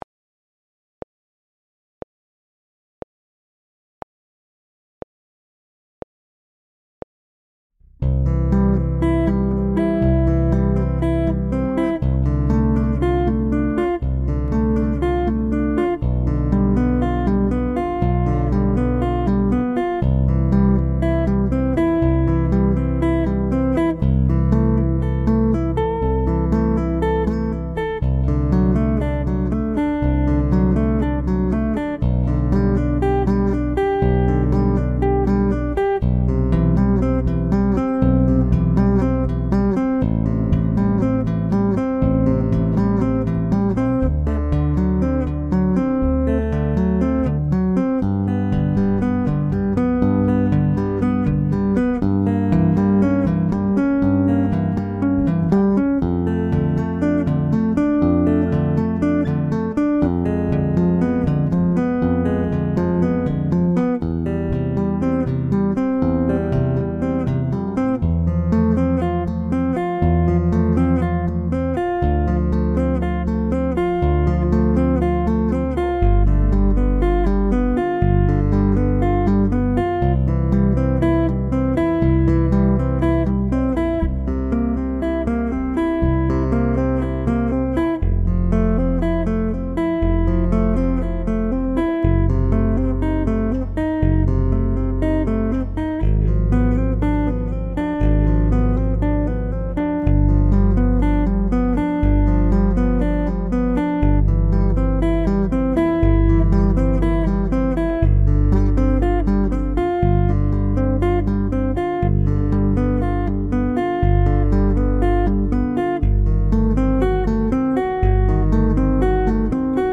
• Instrumental